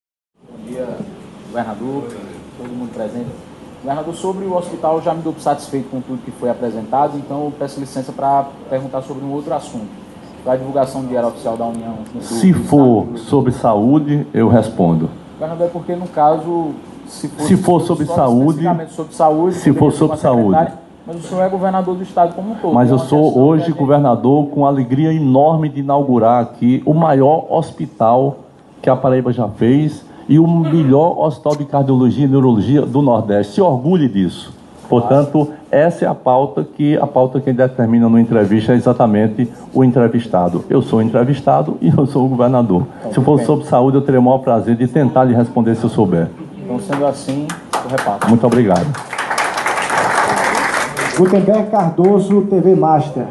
A prova está no seu comportamento furibundo e arbitrário, revelado durante uma entrevista concedida quando da inauguração do Hospital Metropolitano de Santa Rita, nessa quarta (dia 4). O governador peitou os jornalistas presentes, afirmando com um autoritarismo incomum que ele, e somente ele, é quem pauta as perguntas.